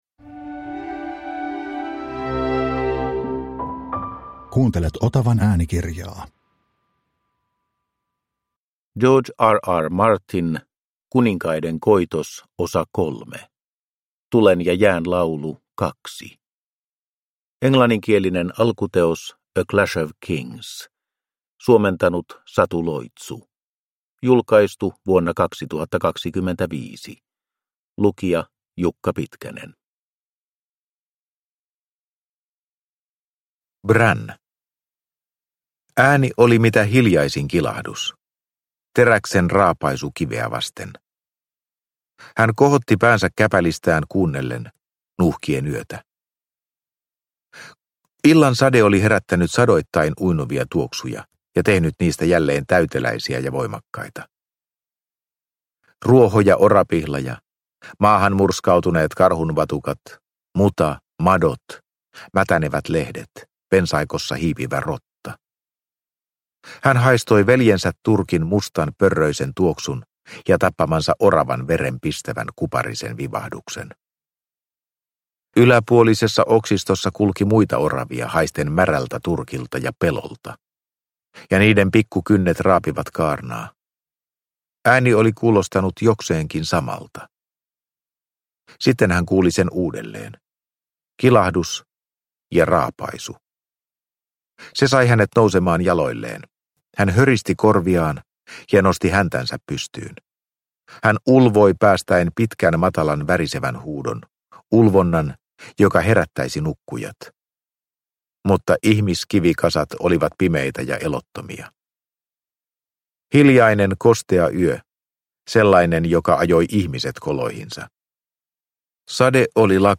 Kuninkaiden koitos 3 – Ljudbok
Äänikirjan kolmas osa.